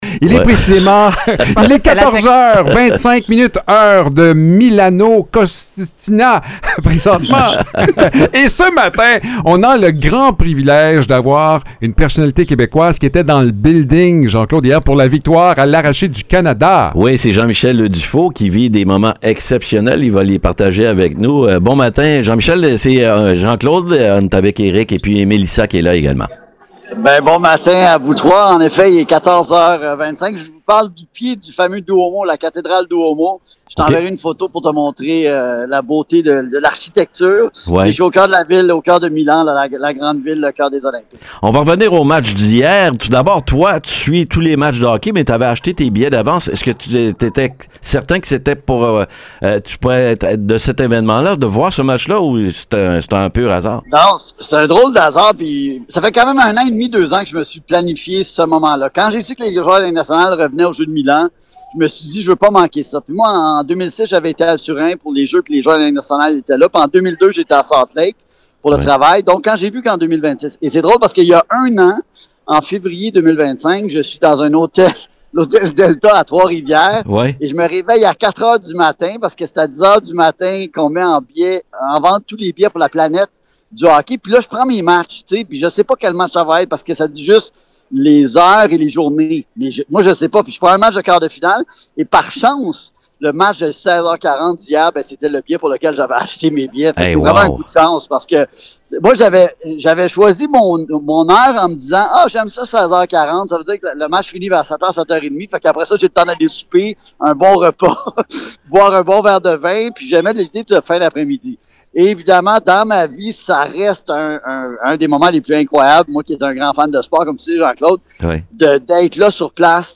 en direct des Jeux olympiques, où il a assisté à la victoire du Canada contre la Tchéquie.